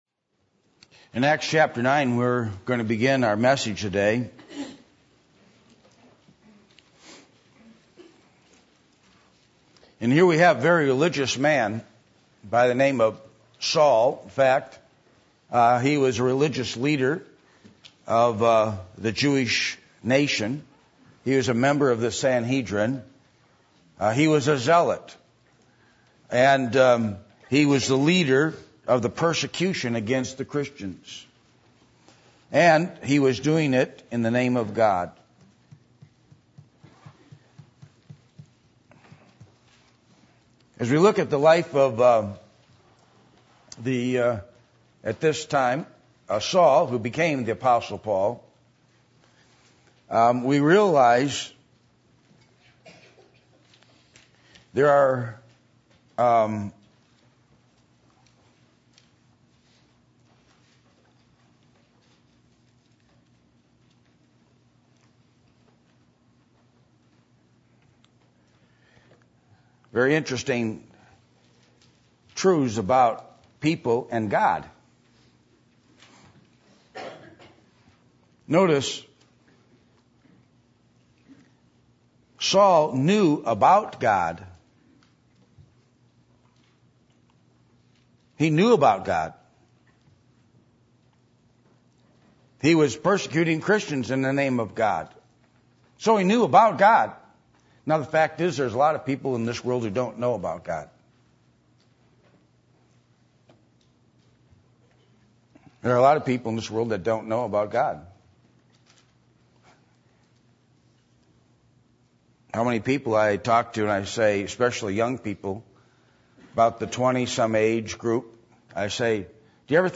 Passage: Acts 9:1-16 Service Type: Sunday Morning %todo_render% « Increase Our Faith A Special Calling